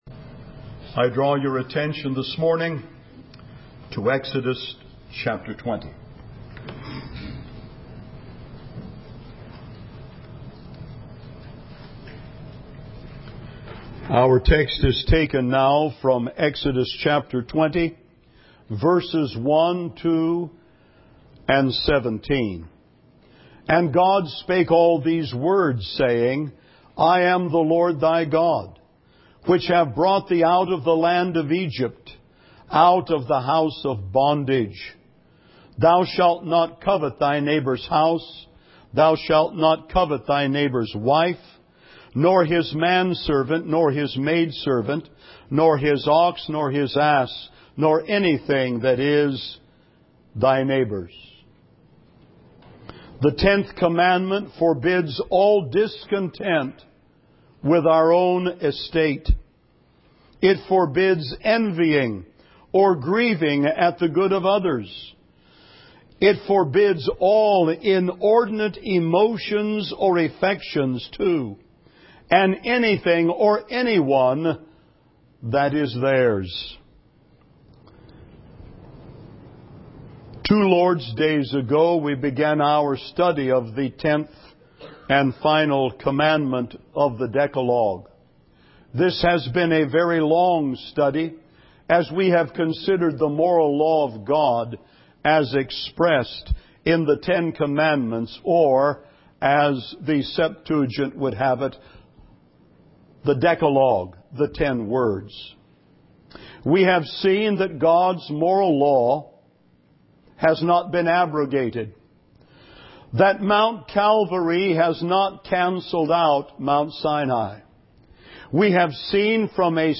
Sermons - Sovereign Grace Baptist Church of Silicon Valley